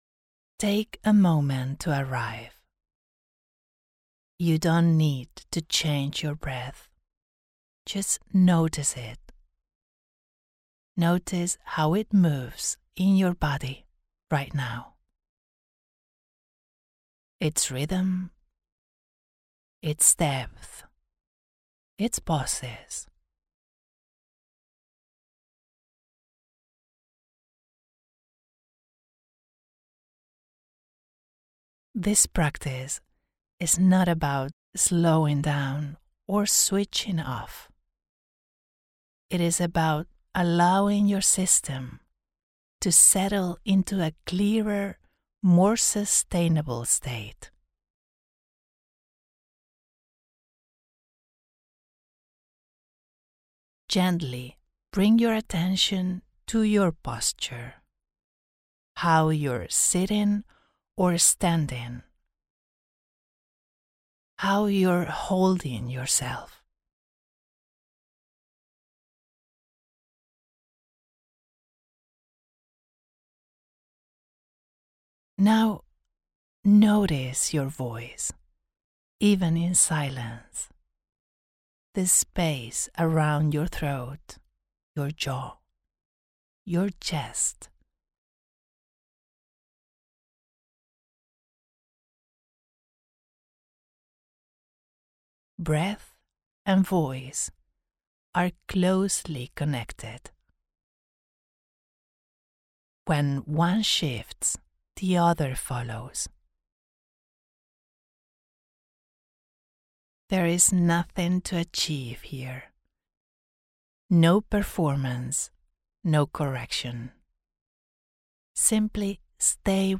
Breath-with-me-AOP-FBR.mp3